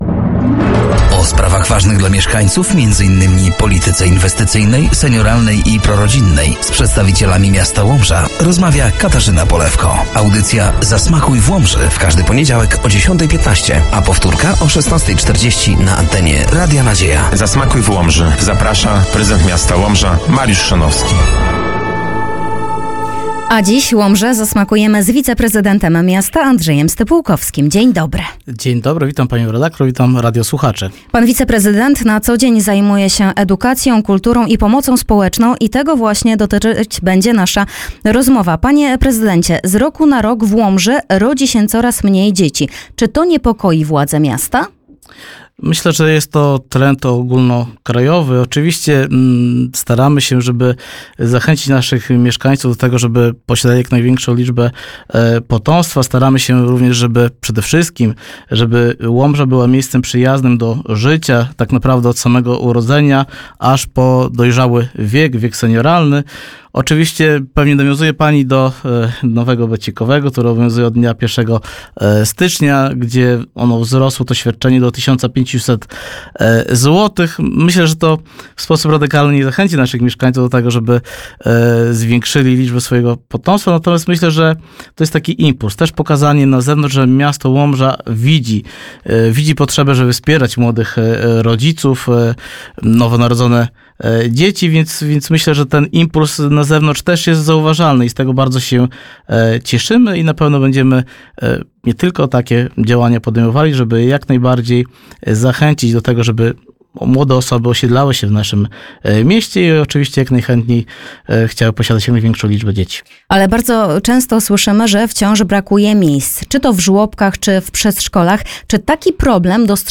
Gościem piątej audycji był Wiceprezydent Miasta Łomża – Andrzej Stypułkowski.